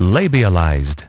Transcription and pronunciation of the word "labialized" in British and American variants.